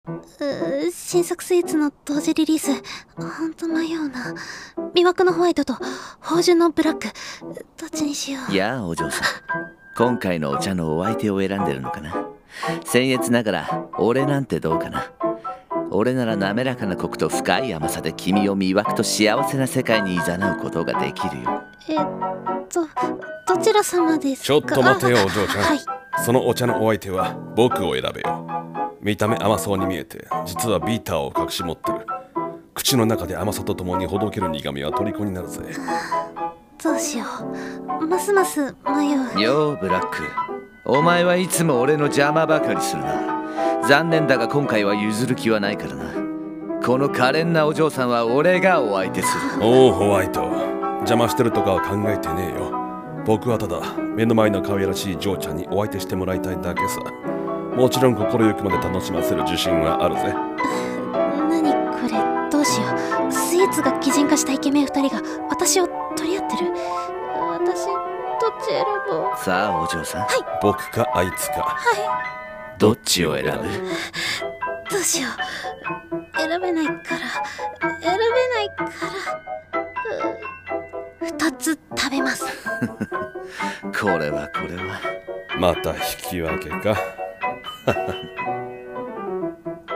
【三人声劇】Black Or White~君はどちらを選ぶ？~